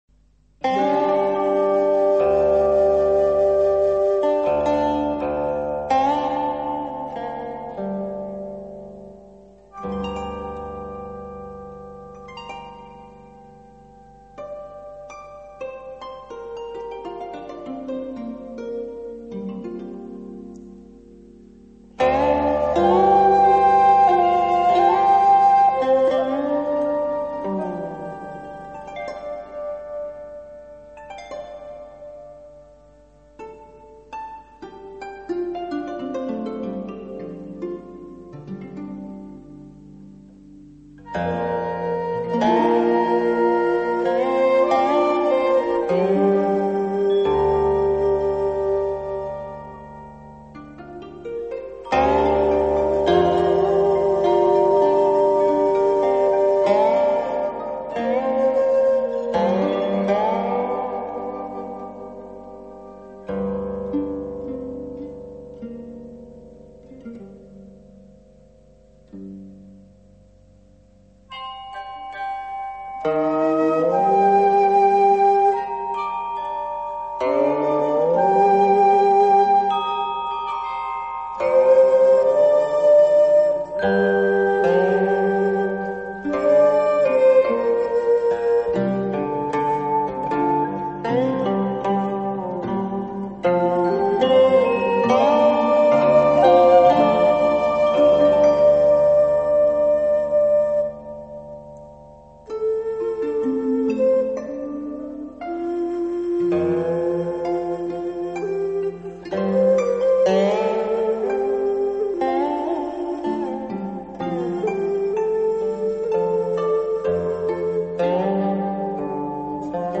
中国古典音乐欣赏
婉约詞风 低吟浅唱 弦绝音苦
小合奏